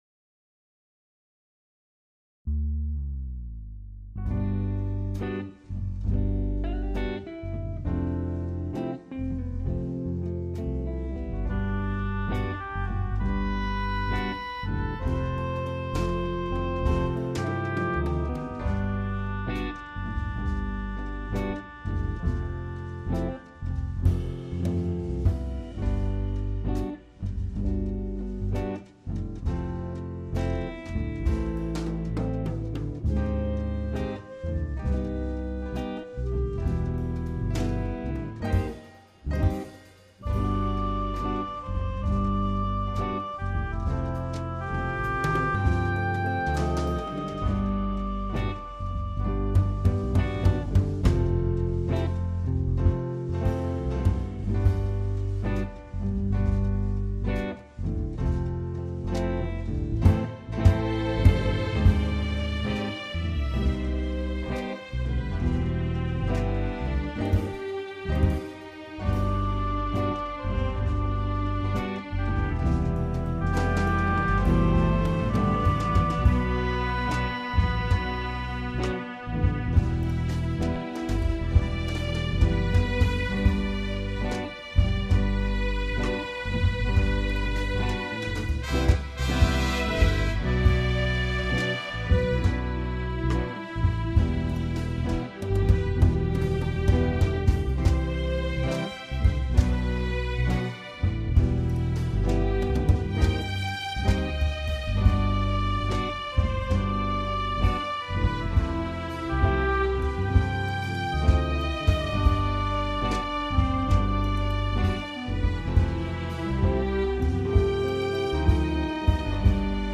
The Haunting - instrumental version